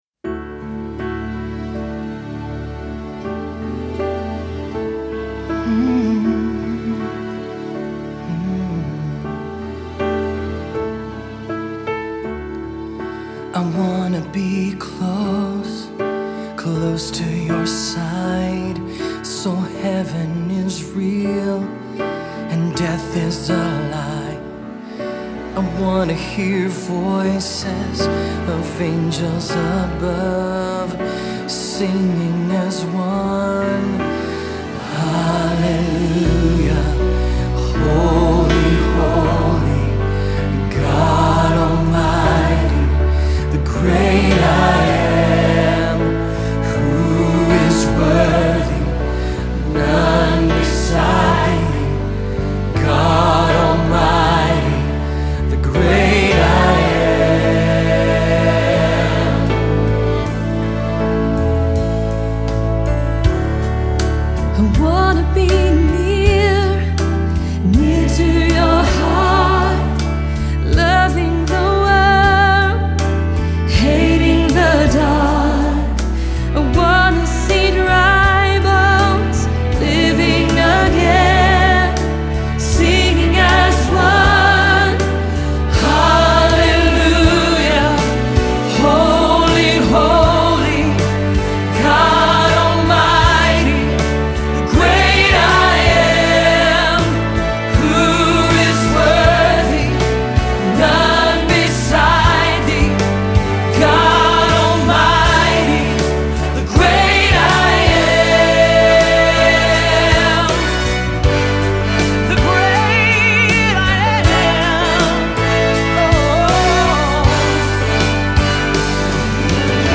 Great I Am (mp3) For Concert Choir, Adoration Band, Praise & Worship, Beethoven / Bach Orchestra 4.28.2017
Choir
Orchestra